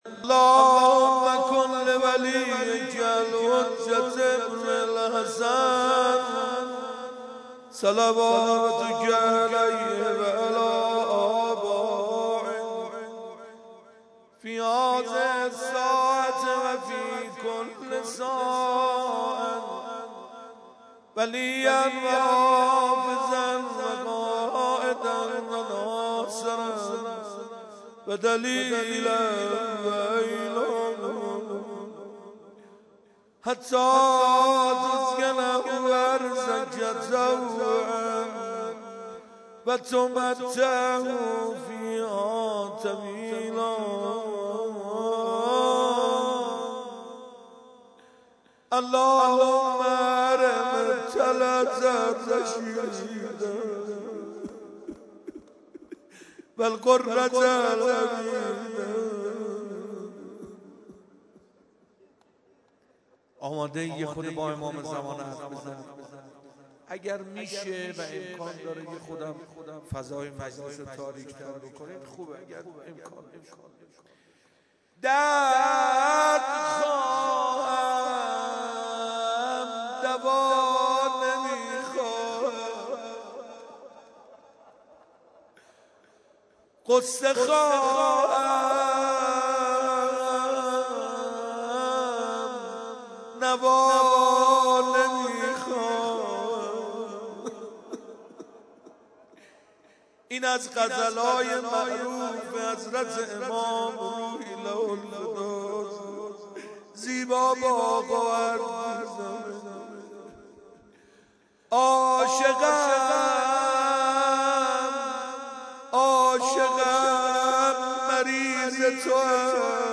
صوت مداحی حاج حسین سازور و حاج سعید حدادیان در مراسم شکرانه سلامتی رهبری
مراسم شکرانه سلامتی رهبر انقلاب با مداحی حاج حسین سازور و حاج سعید حدادیان برگزار شد